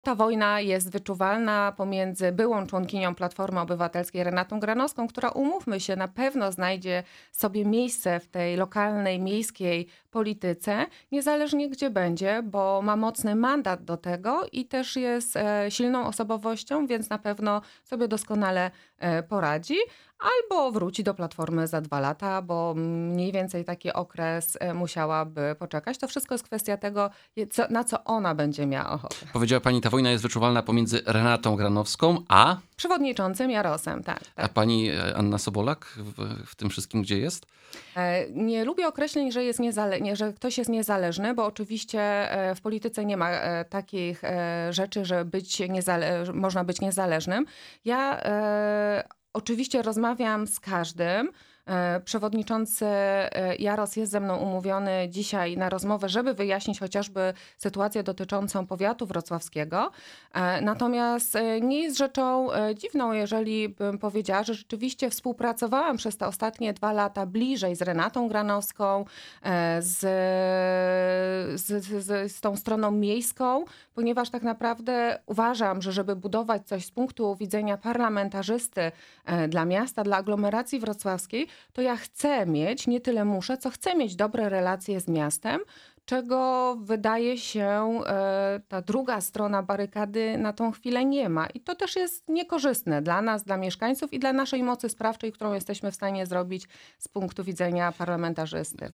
Naszym „Porannym Gościem” była posłanka na Sejm Koalicji Obywatelskiej Anna Sobolak.